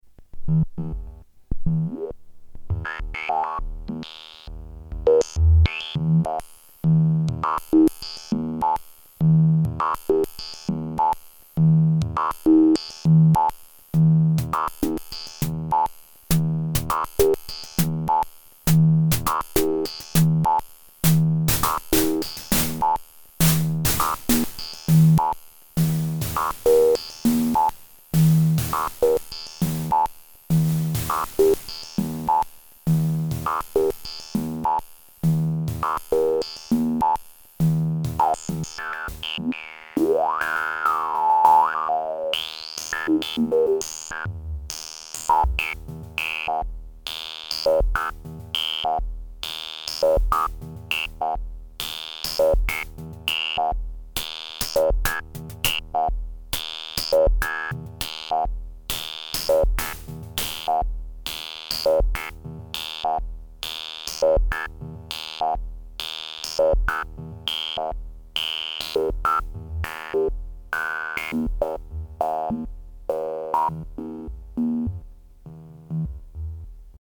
4. VCF & VCA working independently
When using the VCA with white noise (or
Drum machine